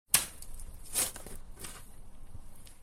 shovel1.ogg